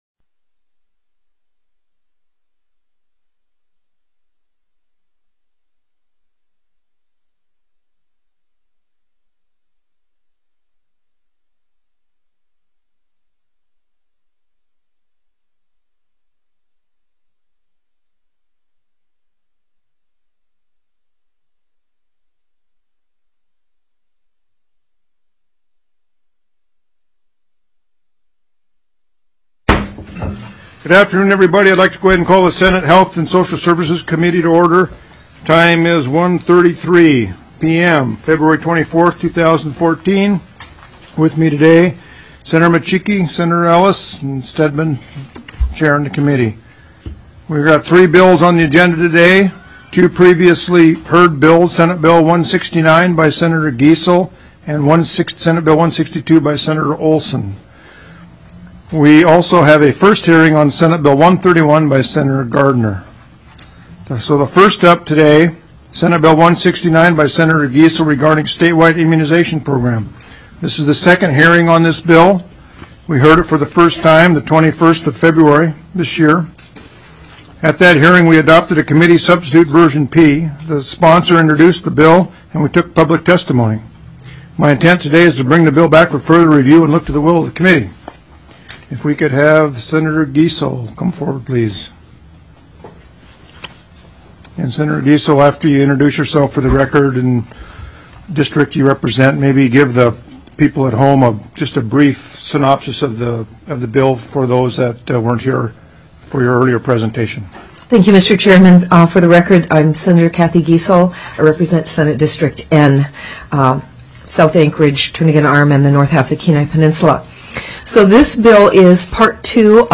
02/24/2014 01:30 PM Senate HEALTH & SOCIAL SERVICES
ALASKA STATE LEGISLATURE SENATE HEALTH AND SOCIAL SERVICES STANDING COMMITTEE